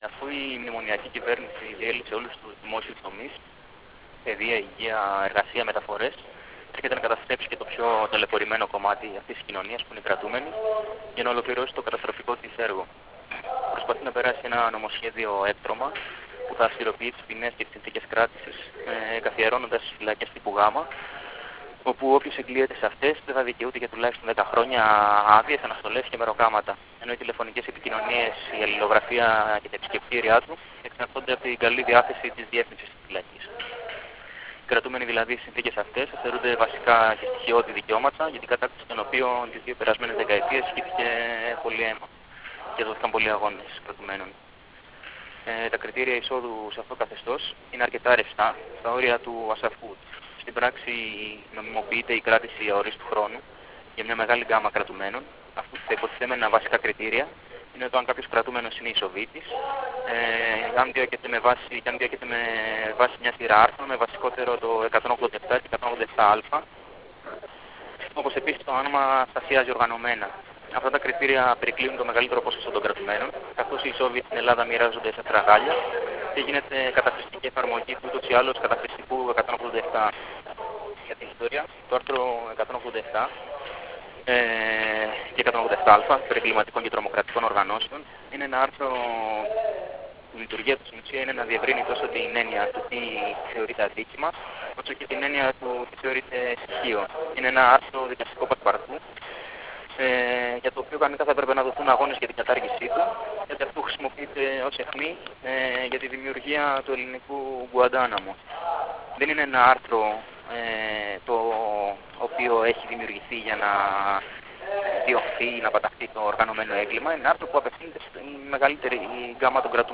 κατά τη διάρκεια συνέντευξης Τύπου ενάντια στο «ελληνικό Γκουαντάναμο», για το νομοσχέδιο του υπουργείου Δικαιοσύνης που εγκαθιδρύει φυλακές υψίστης ασφαλείας για κρατούμενους με τον αντιτρομοκρατικό νόμο.